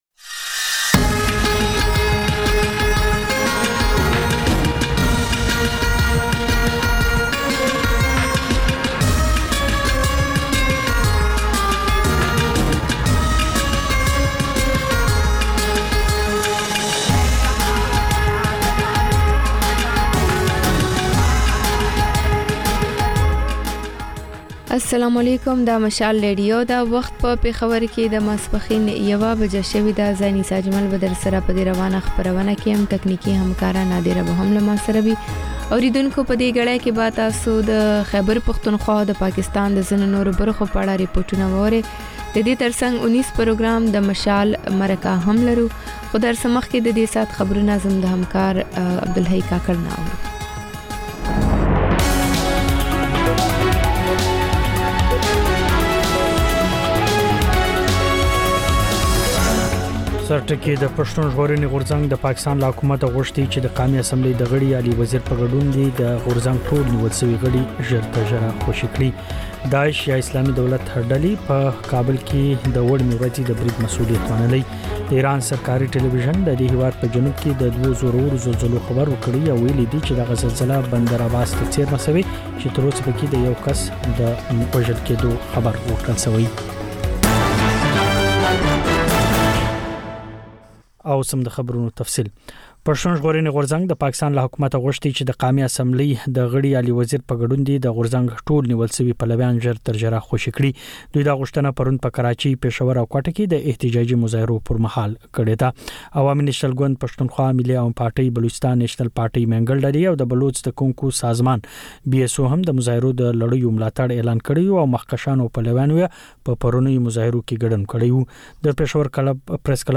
د مشال راډیو لومړۍ ماسپښينۍ خپرونه. په دې خپرونه کې تر خبرونو وروسته بېلا بېل رپورټونه، شننې، مرکې خپرېږي. په دې ګړۍ کې اوونیزه خپرونه هم خپرېږي.